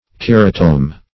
Meaning of keratome. keratome synonyms, pronunciation, spelling and more from Free Dictionary.
Search Result for " keratome" : The Collaborative International Dictionary of English v.0.48: Keratome \Ker"a*tome\, n. [Gr. ke`ras, horn + ? to cut.]